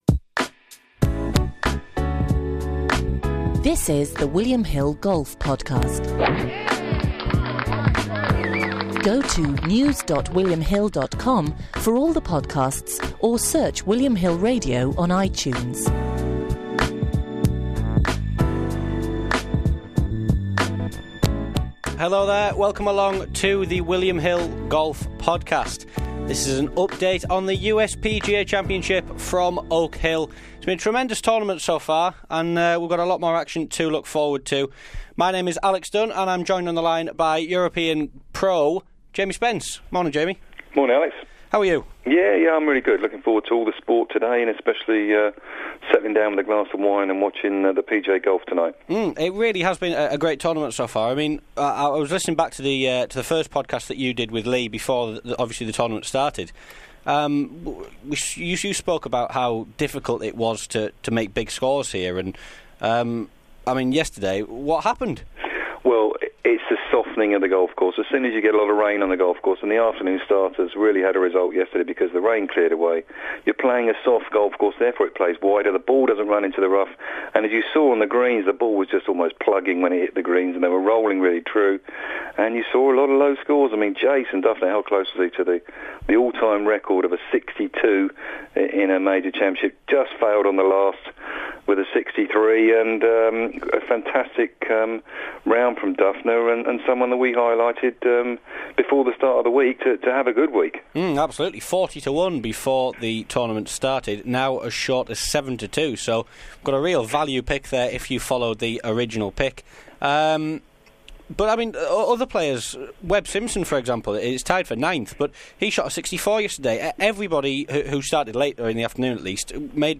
in the studio to look ahead to the action, and looks through each of the main market protagonists at the halfway stage in detail.